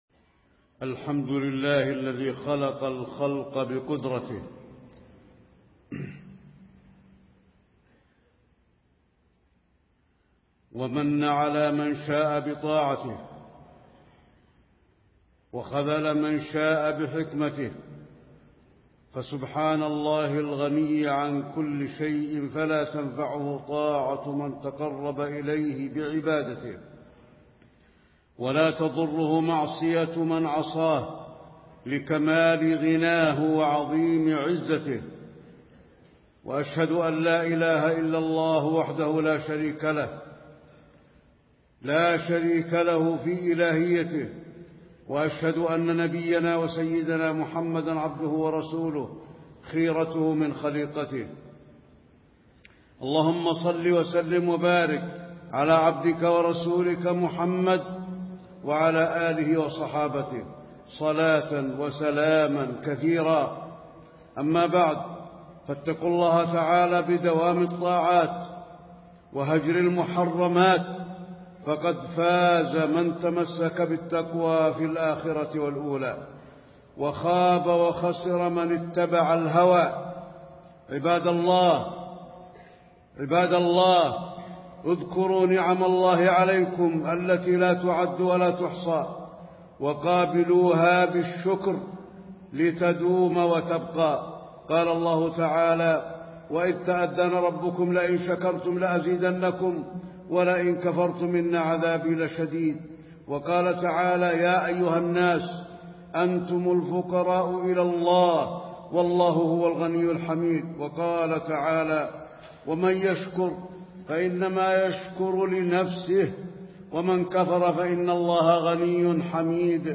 تاريخ النشر ٢ شوال ١٤٣٤ هـ المكان: المسجد النبوي الشيخ: فضيلة الشيخ د. علي بن عبدالرحمن الحذيفي فضيلة الشيخ د. علي بن عبدالرحمن الحذيفي لزوم الطاعات بعد انتهاء موسم الخيرات